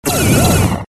Button Sounds
Here is a collection of original button sounds for multimedia presentations.